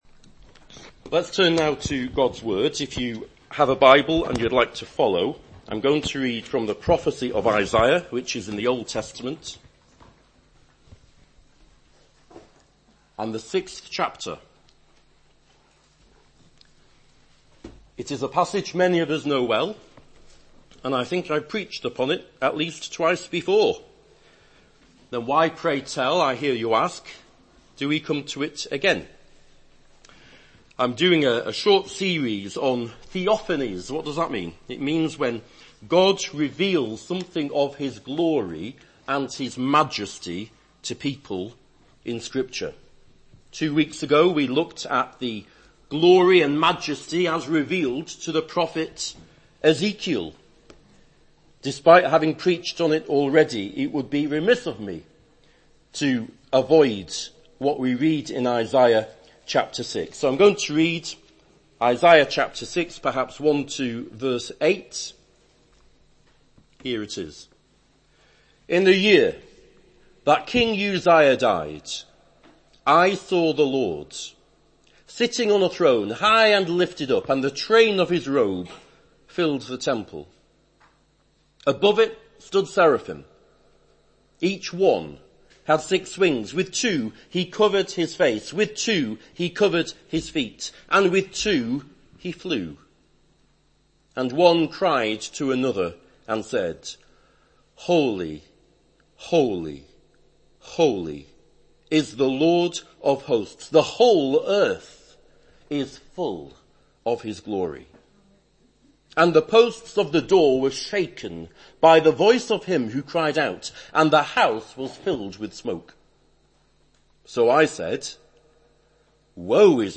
Below is audio of the full service.